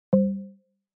terminalBell.mp3